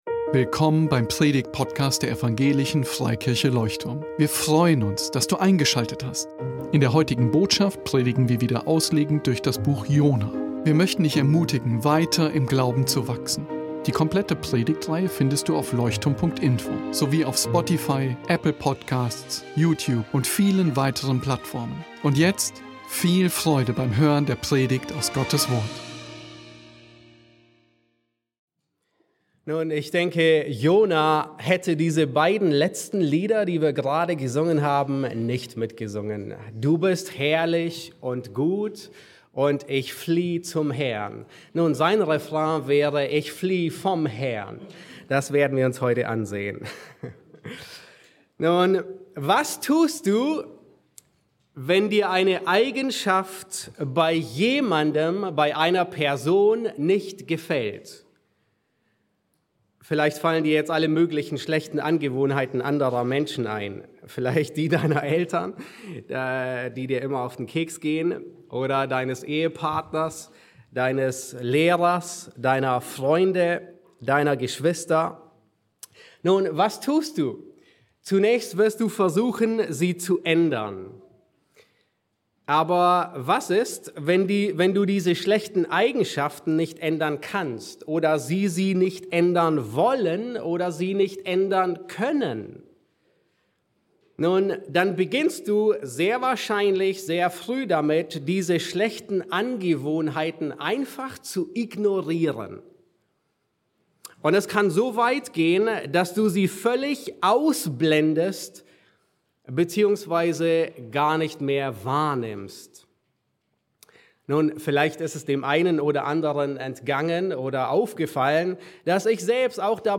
Besuche unseren Gottesdienst in Berlin.